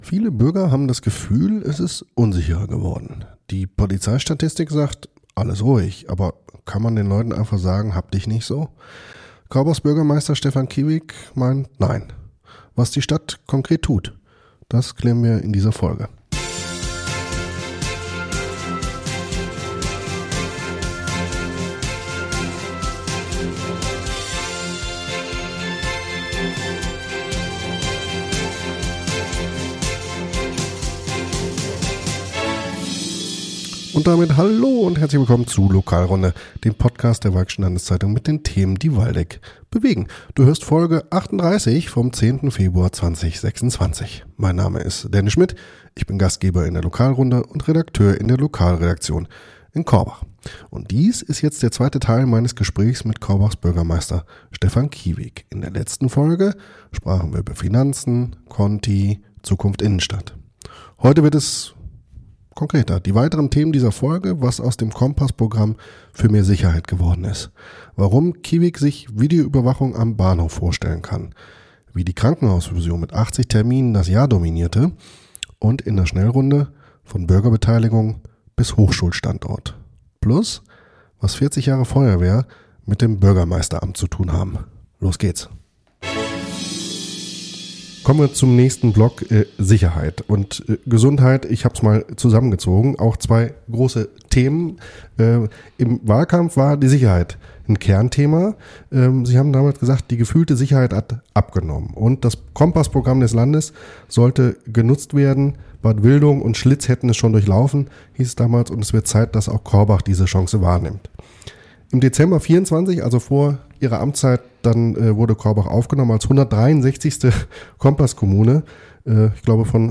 Im zweiten Teil des Interviews mit Korbachs Bürgermeister Stefan Kieweg geht es um Themen, die viele Bürger bewegen: Sicherheit in der Hansestadt, die Krankenhausfusion und die verzweifelte Suche nach Kinderärzten.